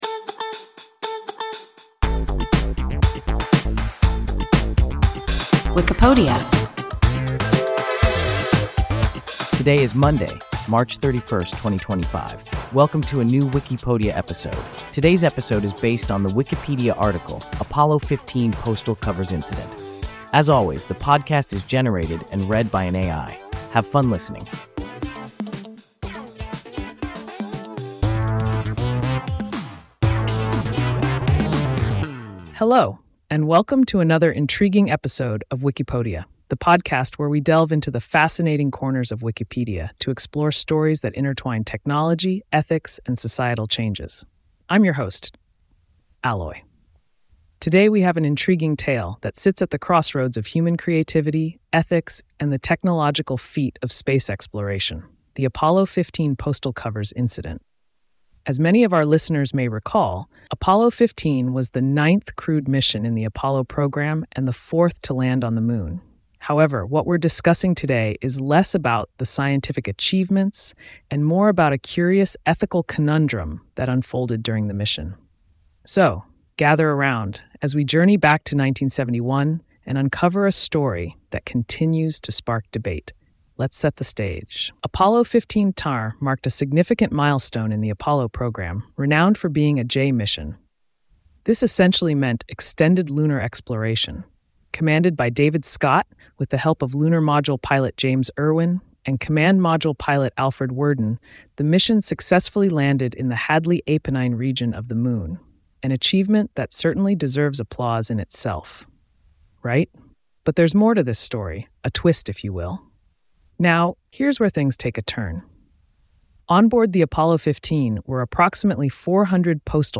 Apollo 15 postal covers incident – WIKIPODIA – ein KI Podcast